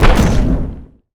energy_blast_small_05.wav